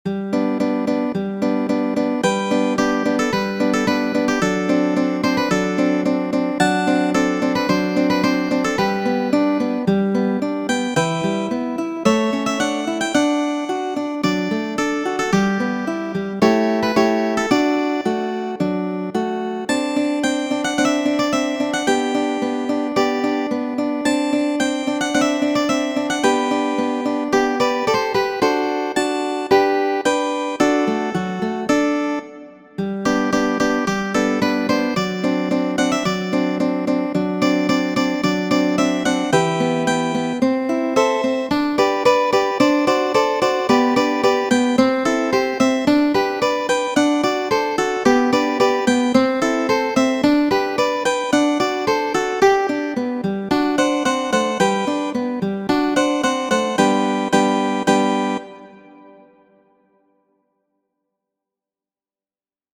La linterna, estas duo por gitaro, verkita de Maŭro Ĝuljani la 19-an jarcenton..